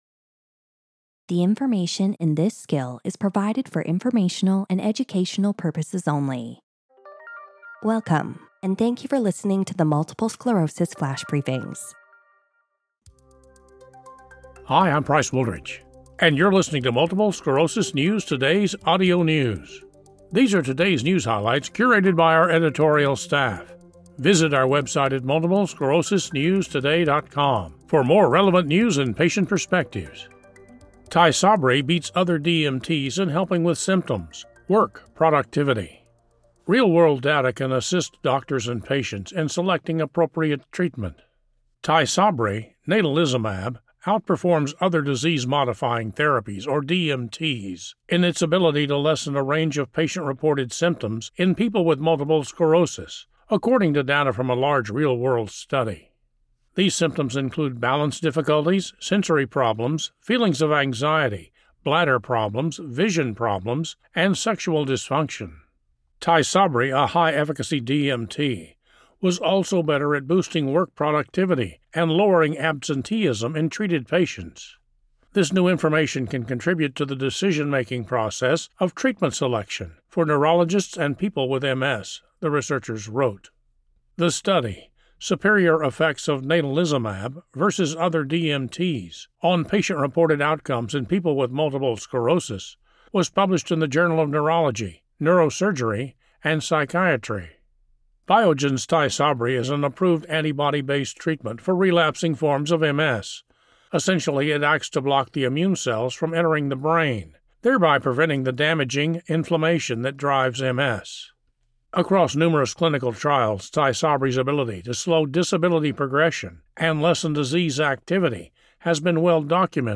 reads a news article on how Tysabri outperformed other MS treatments at lessening symptoms and improving work productivity.